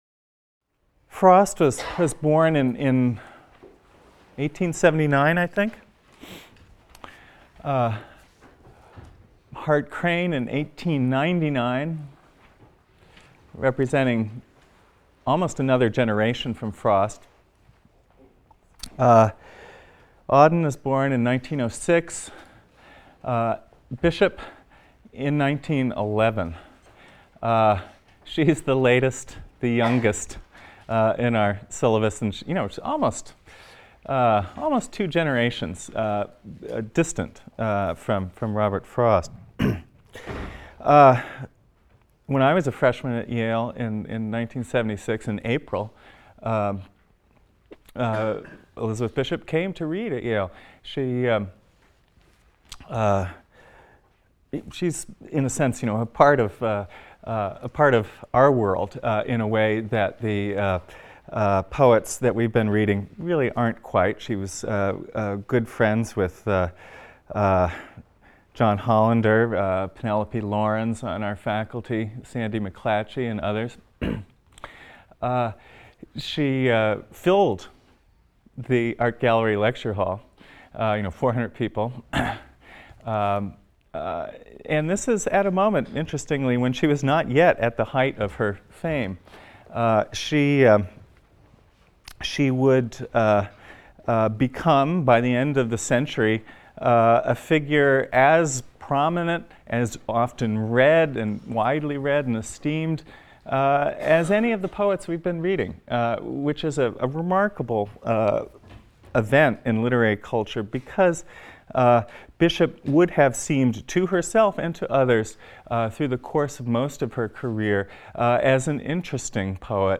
ENGL 310 - Lecture 24 - Elizabeth Bishop | Open Yale Courses